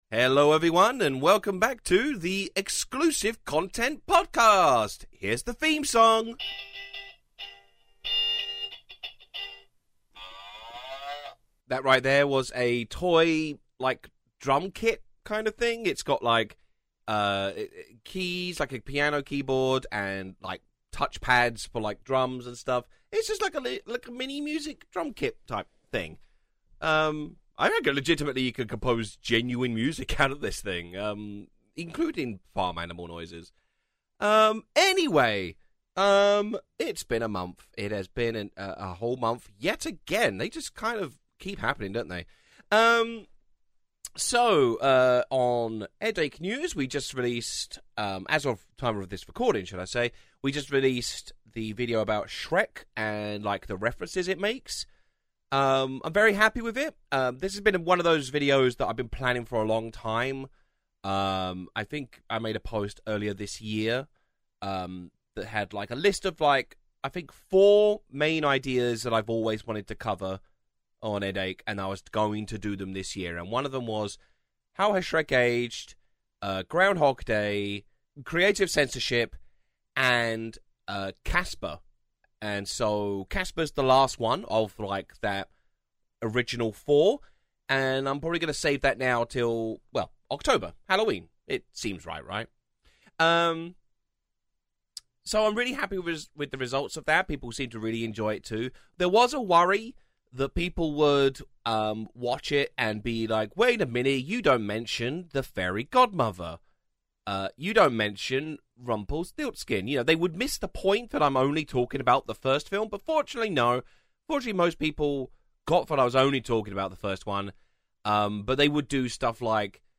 Our guest this month is the legendary Mr Weebl, the fellow behind Badger Badger Badger, Narwhals, Amazing Horse and all the other internet earworms of the past 20 years, as we chat about cartoon, webtoons and more!